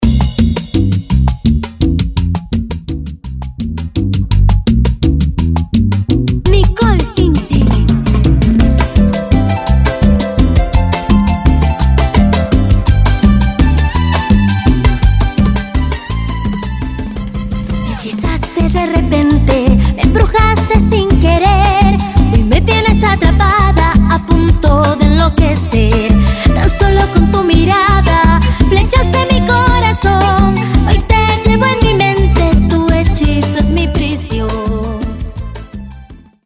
Etiqueta: Cumbia